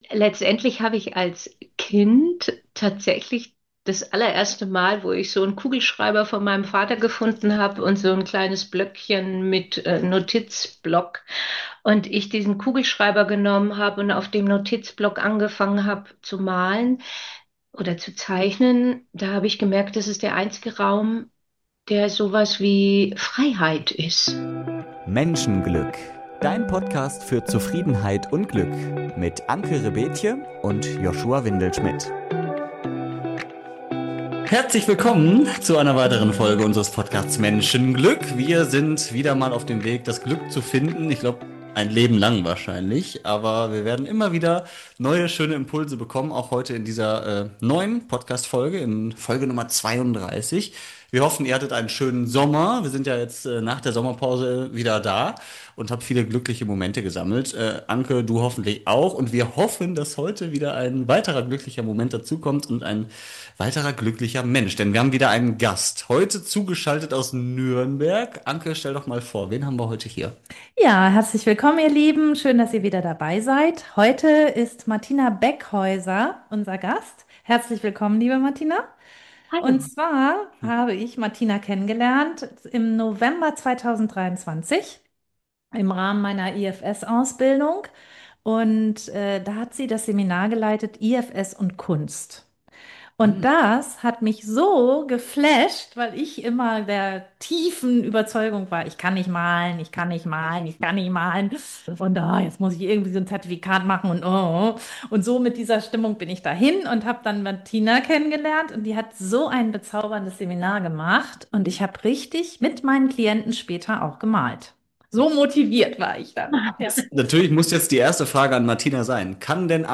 Ein Gespräch über innere Heilung, persönliche Entwicklung und das Streben nach echtem Menschenglück.